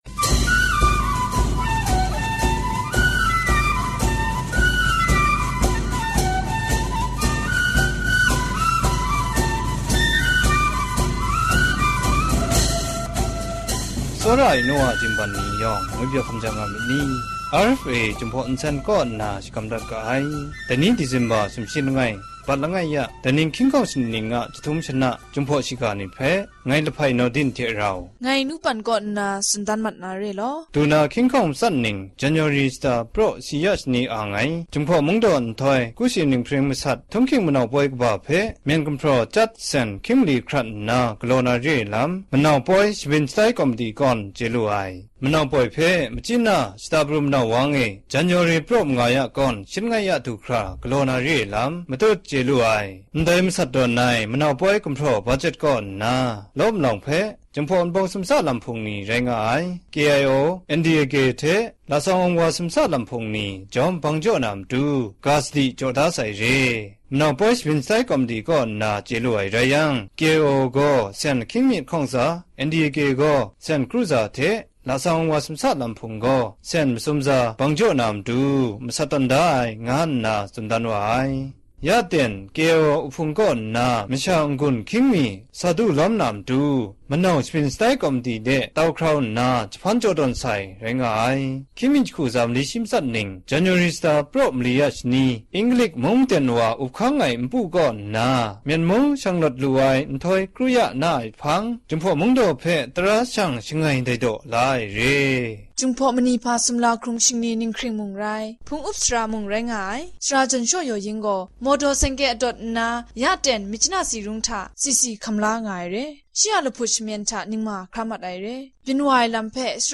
ကခဵင်ဘာသာအသံလြင့်အစီအစဉ်မဵား